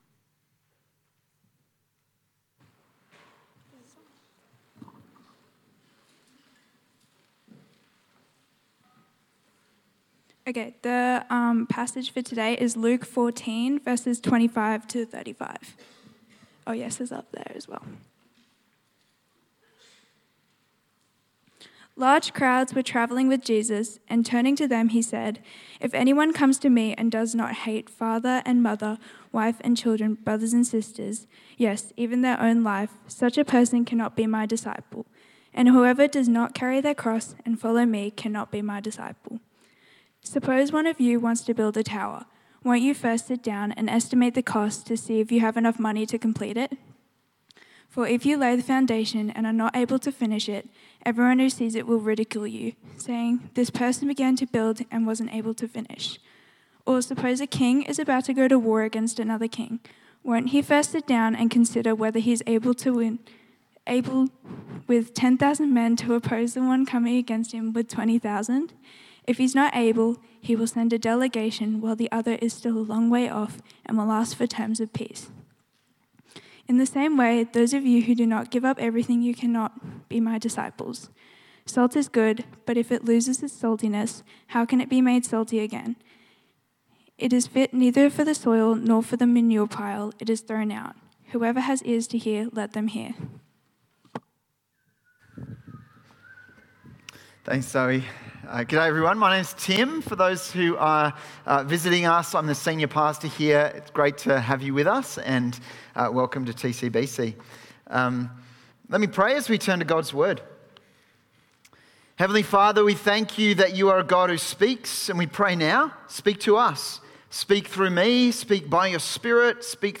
Service Type: 6PM